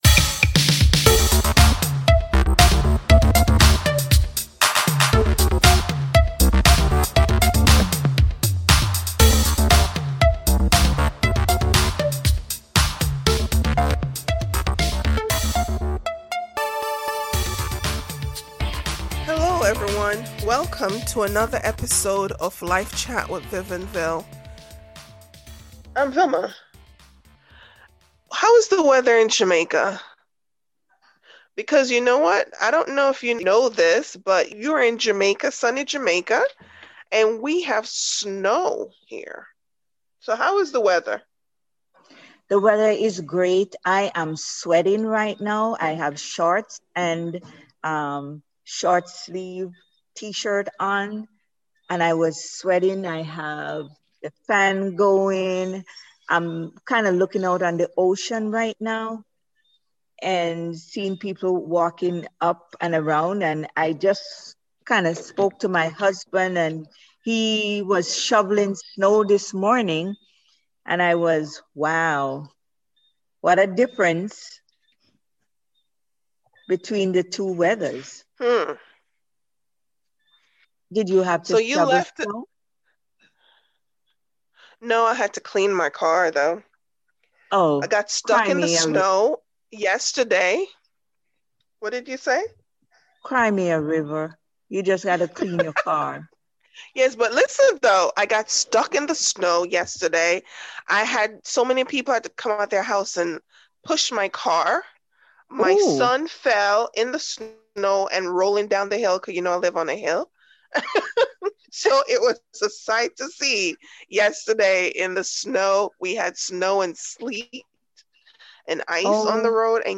Hosts and guest discussed the needs and battles in a 3rd world country during a pandemic.